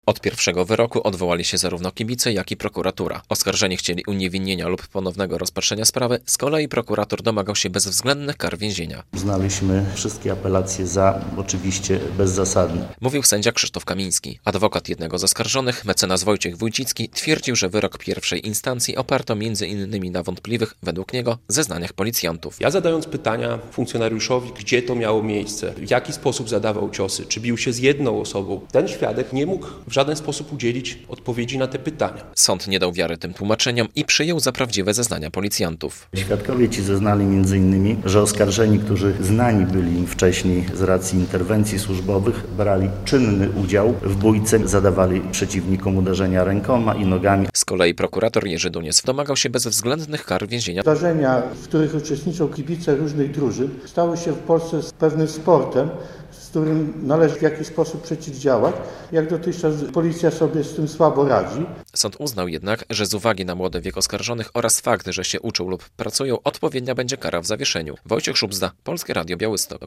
Kibice Jagiellonii skazani za udział w bójce - relacja